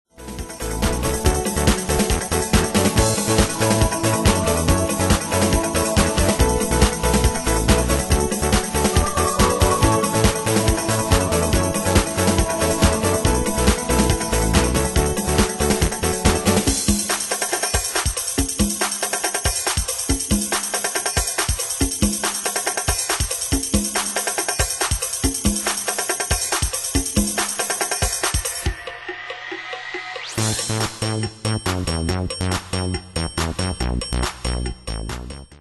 Demos Midi Audio
Style: Dance Année/Year: 1996 Tempo: 140 Durée/Time: 4.57
Danse/Dance: Dance Cat Id.
Pro Backing Tracks